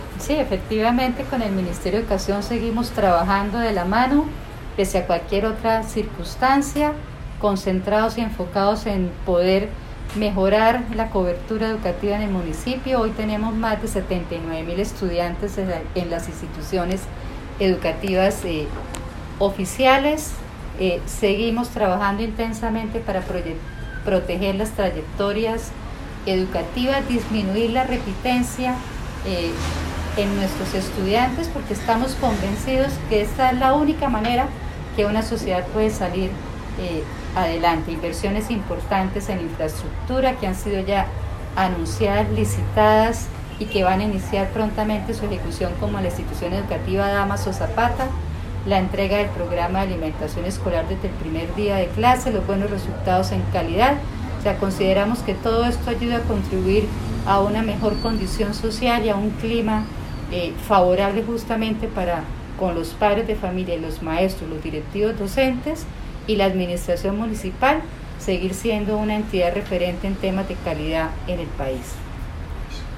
audio: Ana Leonor Rueda, secretaria de Educación